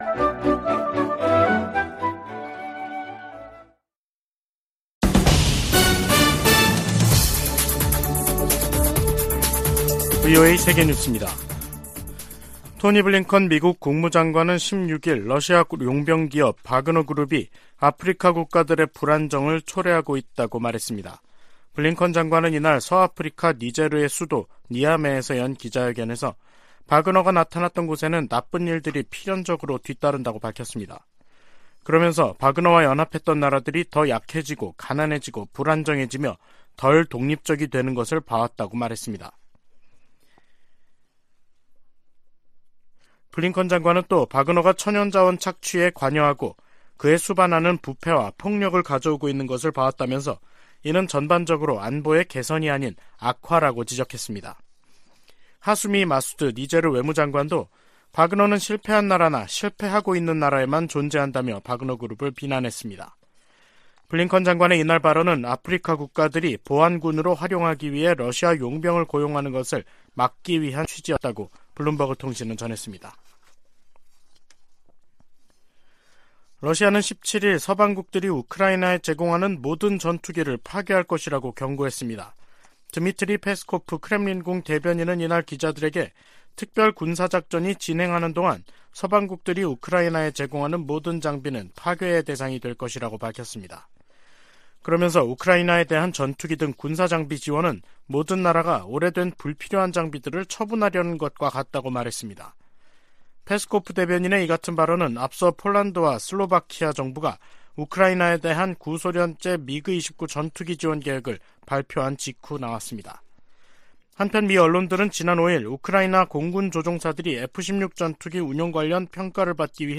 VOA 한국어 간판 뉴스 프로그램 '뉴스 투데이', 2023년 3월 17일 3부 방송입니다. 백악관이 16일 열린 한일 정상회담에 적극적인 환영과 지지 입장을 밝혔습니다. 북한은 16일 대륙간탄도미사일 (ICBM) '화성-17형' 발사 훈련을 실시했다고 밝히고, 그 신뢰성이 검증됐다고 주장했습니다. 미 국방부는 북한의 지속적 도발에도 불구하고 대북 억제력이 작동하고 있다고 강조했습니다.